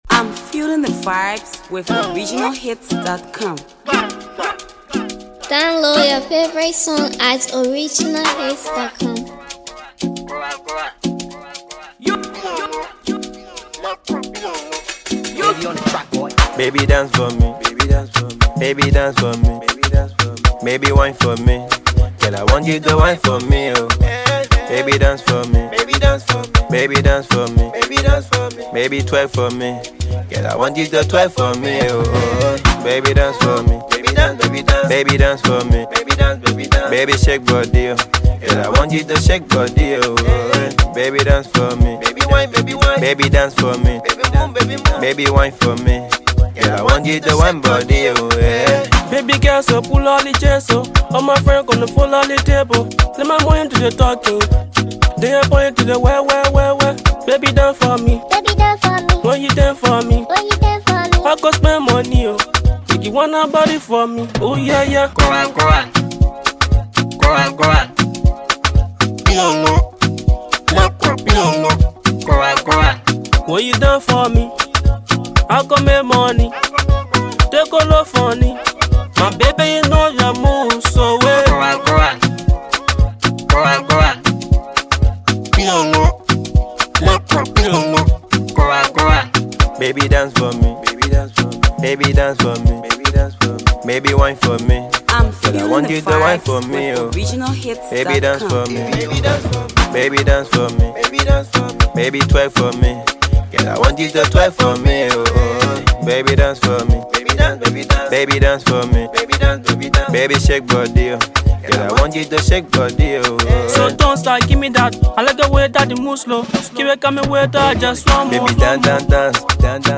The most anticipated club and street banger